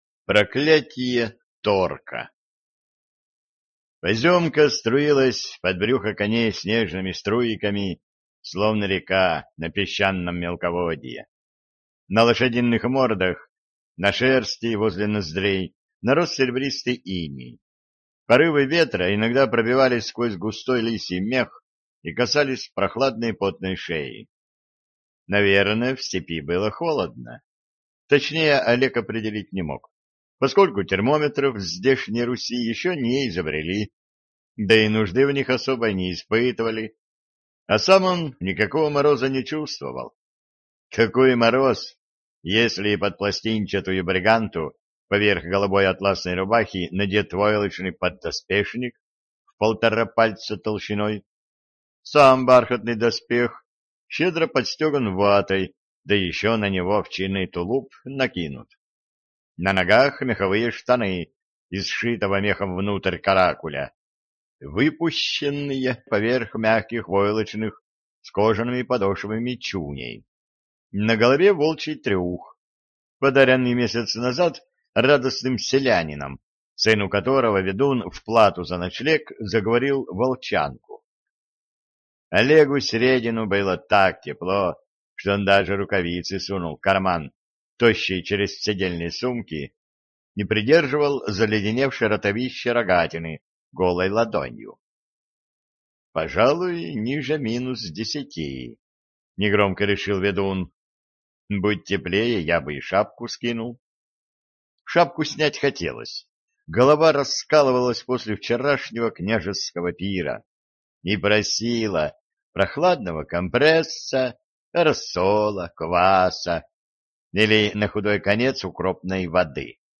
Аудиокнига Медный страж | Библиотека аудиокниг